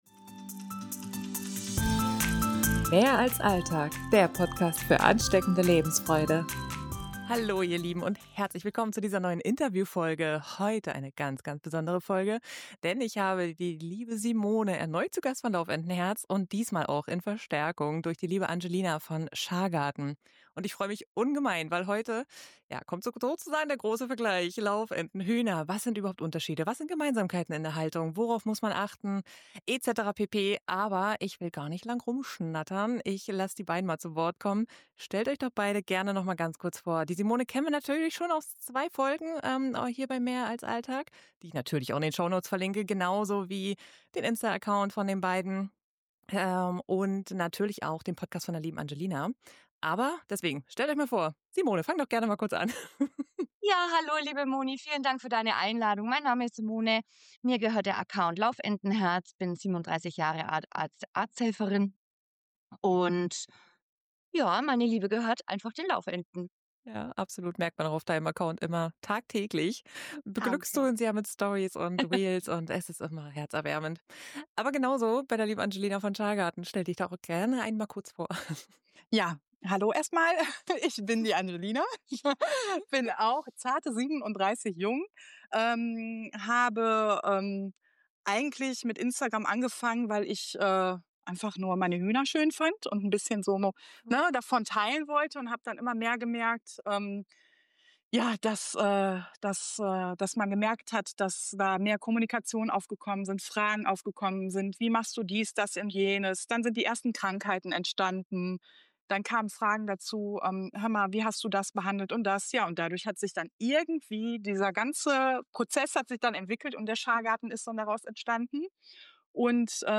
Ein Gespräch voller praktischer Tipps und tiefer Verbundenheit zur Natur.